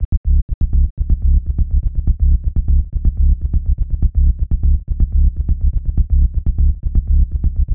• tech house bass samples - C# - 123.wav
tech_house_bass_samples_-_C_sharp__-_123_mcD.wav